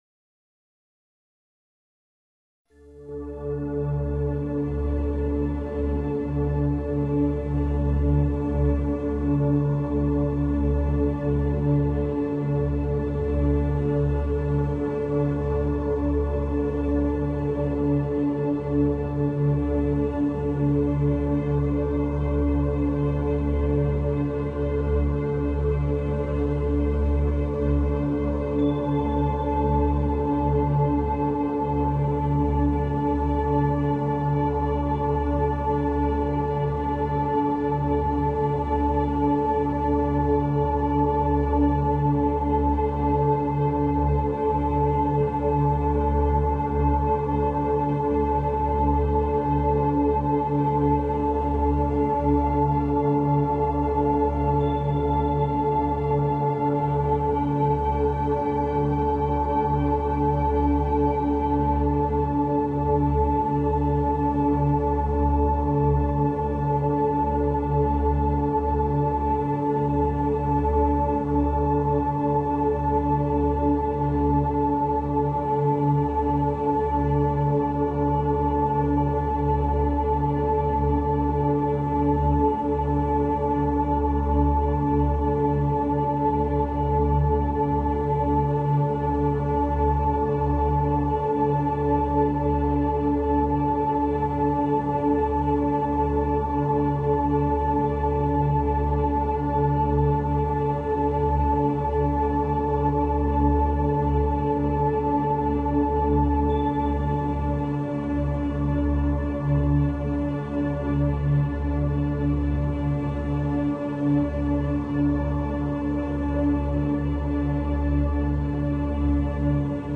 LA – 852 Hz – Frecuencia para volver al orden espiritual.